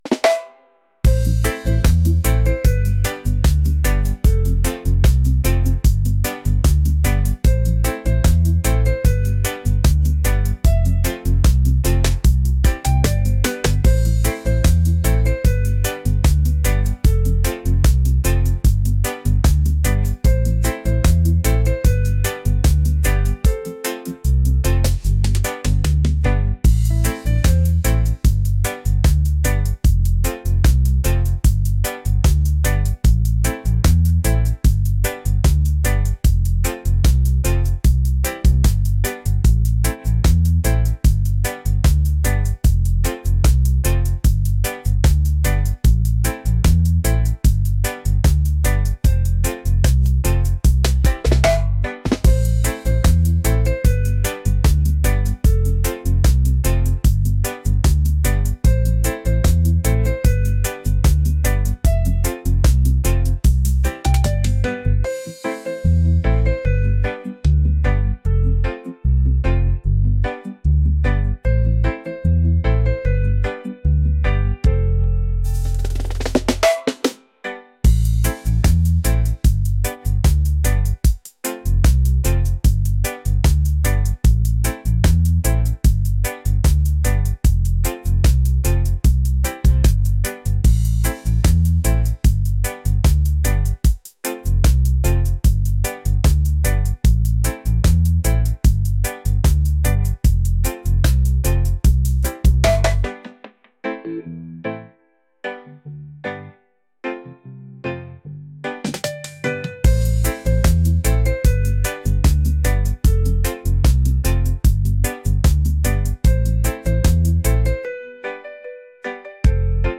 romantic | laid-back | reggae